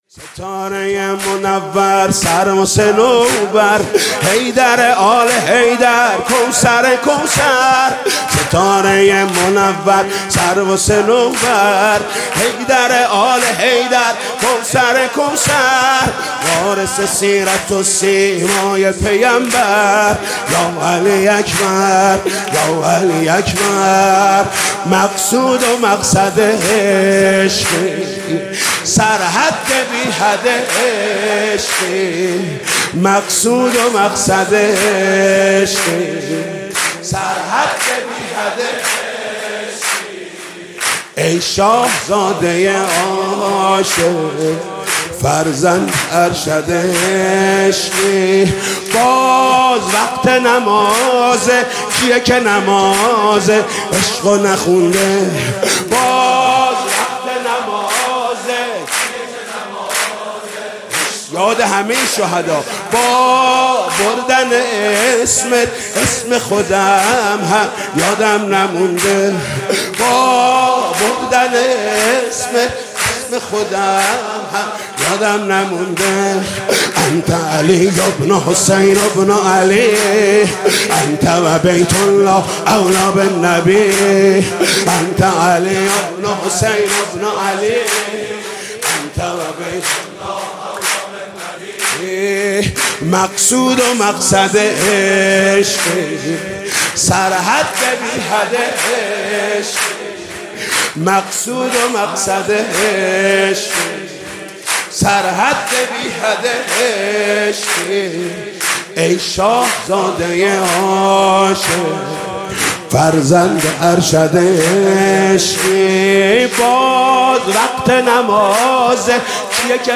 بمناسبت ولادت حضرت علی اکبر -روز جوان